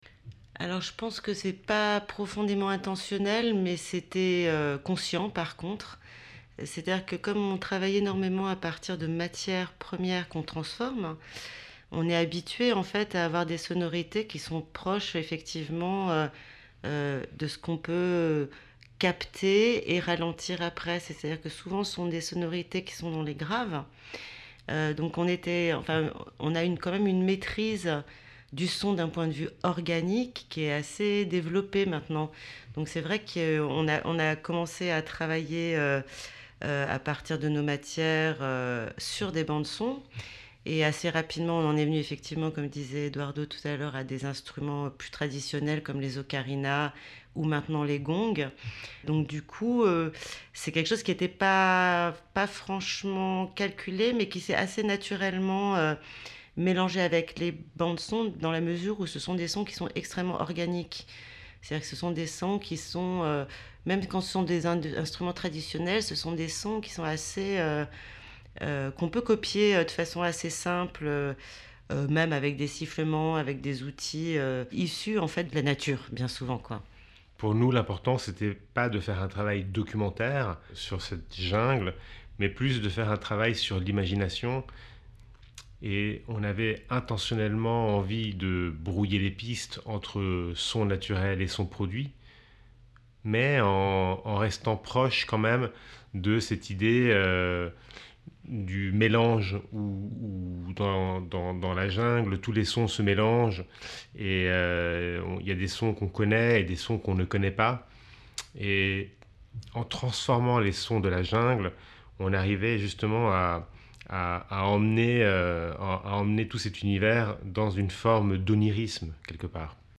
Extrait d’un entretien avec Nova Materia à propos du chef-d’œuvre „Xpujil“ (Made to Measure Vol. 45)
Interview-Deutsclandfunk_4.mp3